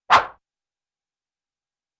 Звуки взмахов